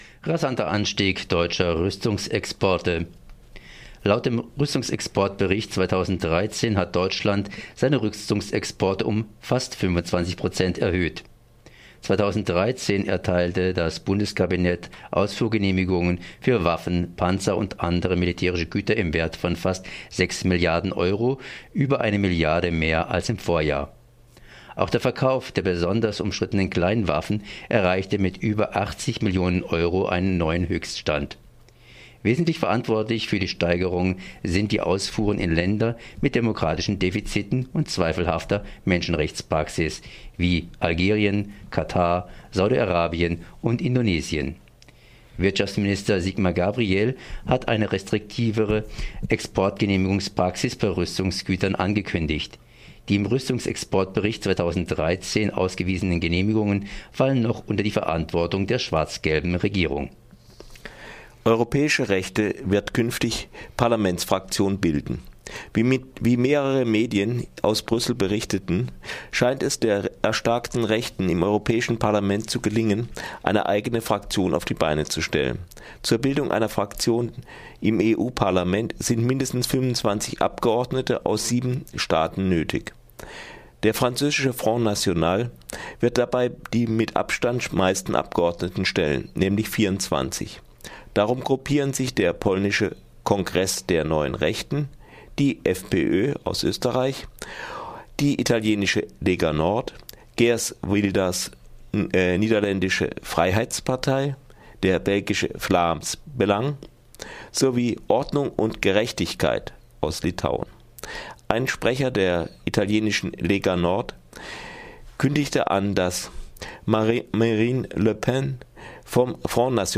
Focus Europa Nachrichten vom Donnerstag, den 12. Juni - 9.30 Uhr